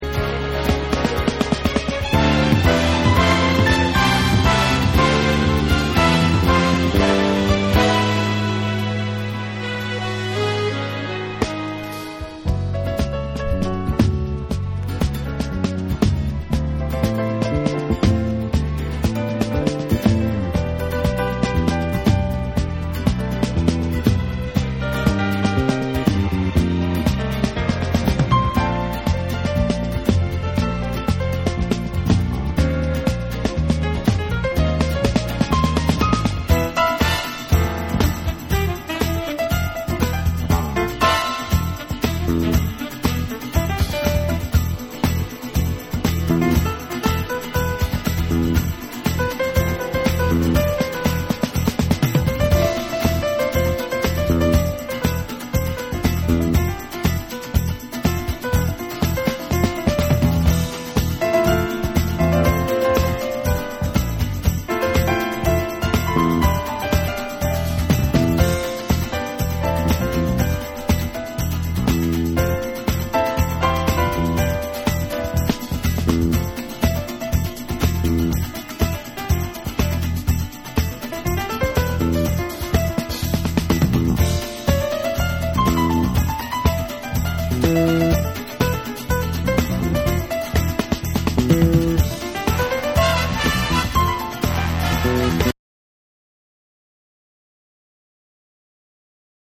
アーバン・メロウなチル・アウト・ナンバーを披露した3（SAMPLE2）。